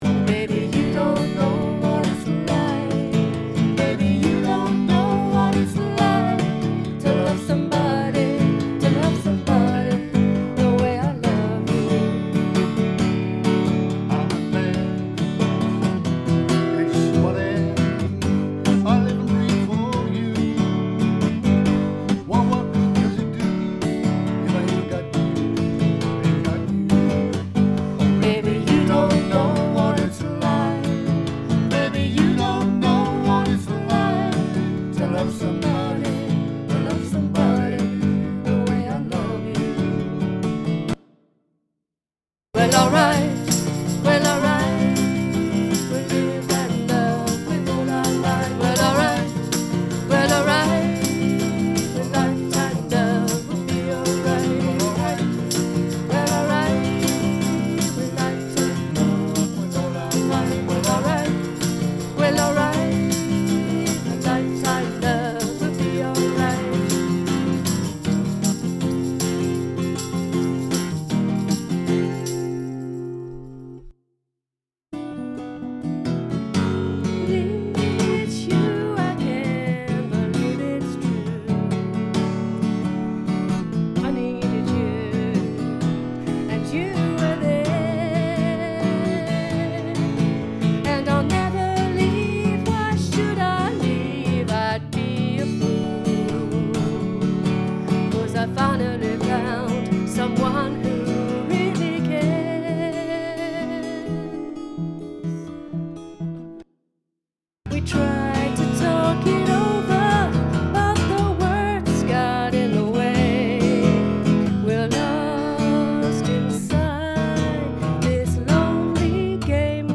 double part harmony, male and female